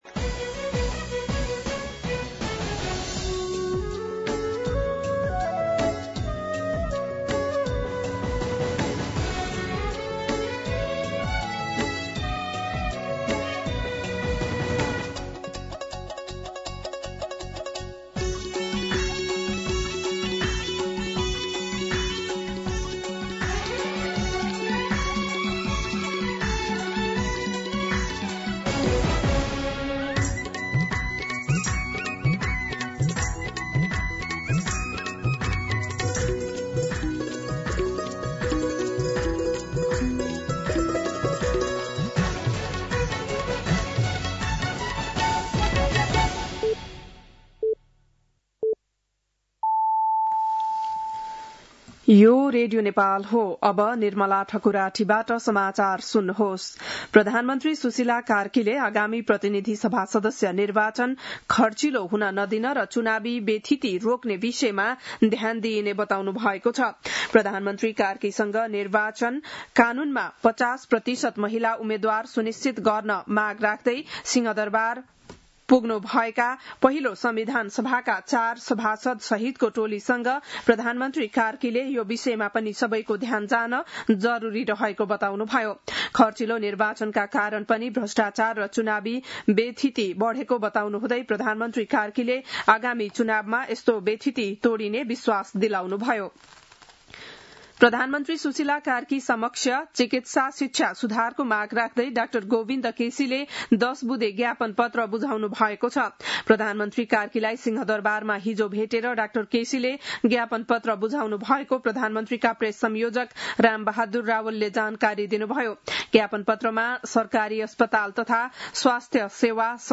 बिहान ११ बजेको नेपाली समाचार : २४ कार्तिक , २०८२